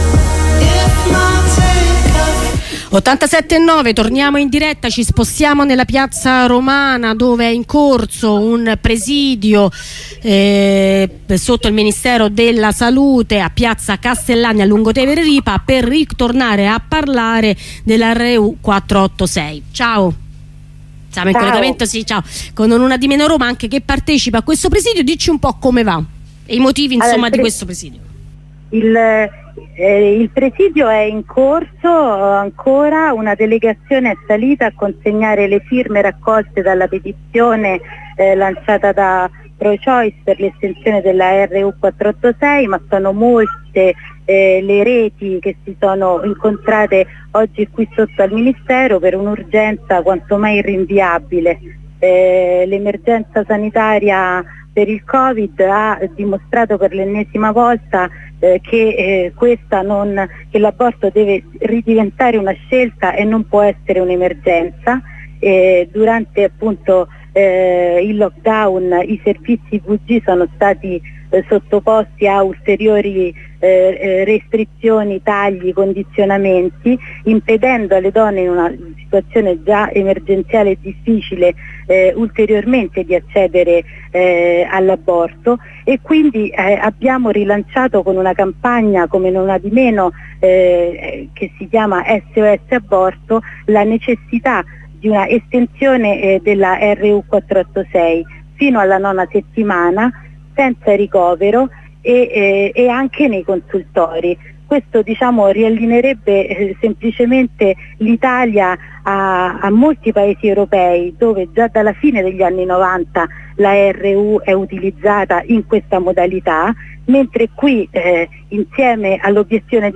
Intervento in apertura del presidio